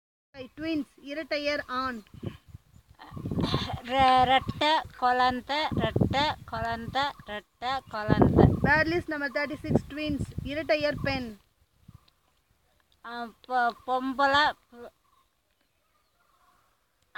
Elicitation of words for kinship terms - Part 6